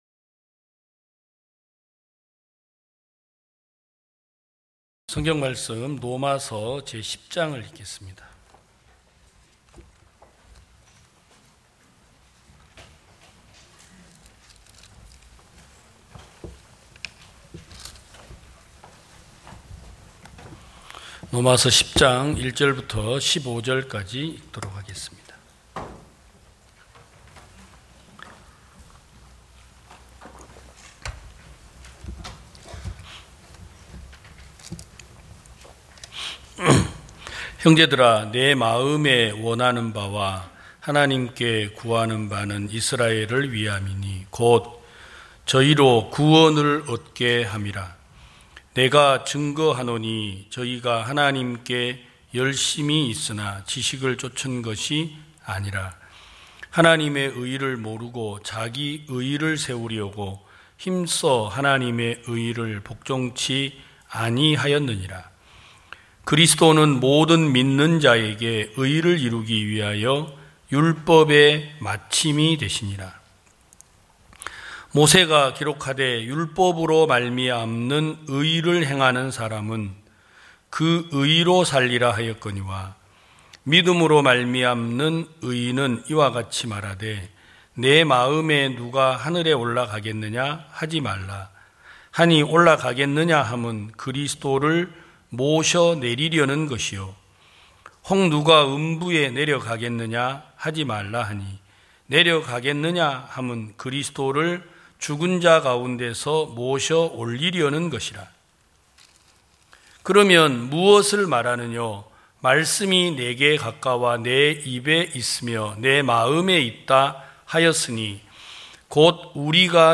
2022년 06월 26일 기쁜소식부산대연교회 주일오전예배
성도들이 모두 교회에 모여 말씀을 듣는 주일 예배의 설교는, 한 주간 우리 마음을 채웠던 생각을 내려두고 하나님의 말씀으로 가득 채우는 시간입니다.